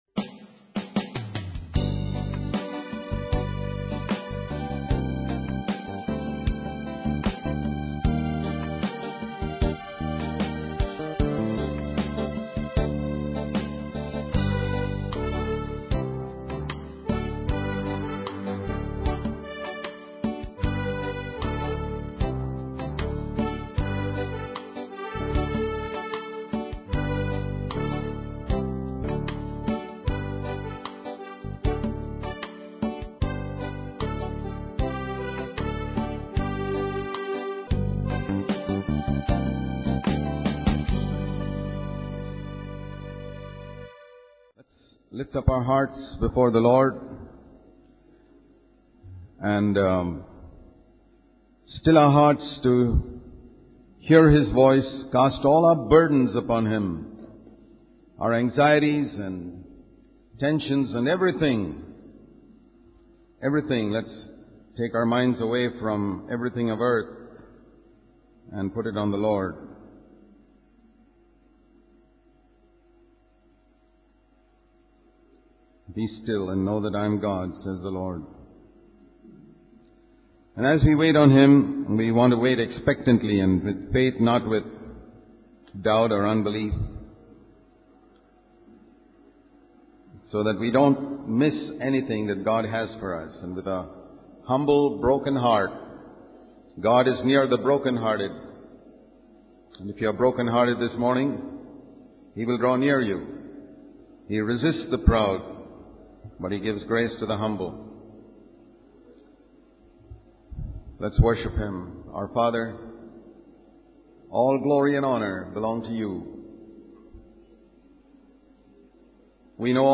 In this sermon, the speaker emphasizes the importance of treating others with kindness and fairness, as taught by Jesus in the Sermon on the Mount. He warns against speaking evil about others and encourages forgiveness and generosity. The speaker also addresses the issue of exploitation and mistreatment of workers, emphasizing the need to pay them fairly.